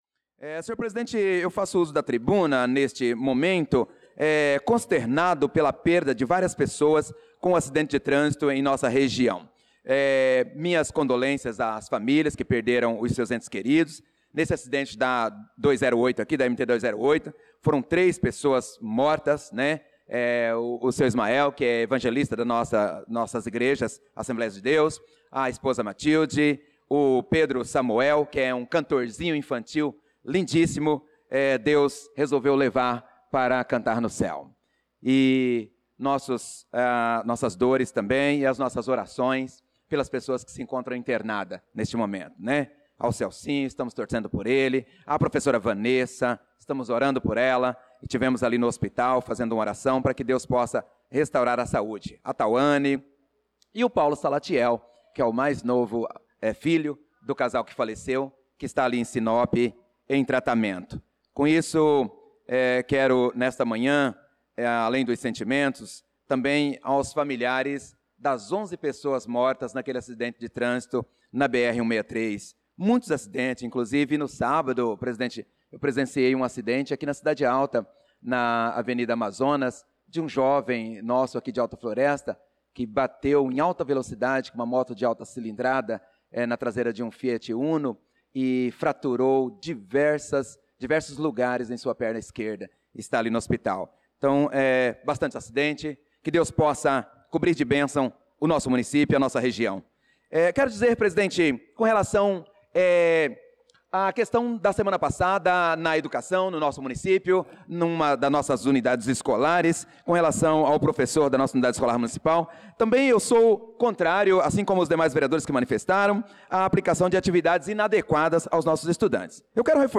Pronunciamento do vereador Prof. Nilson na Sessão Ordinária do dia 11/08/2025.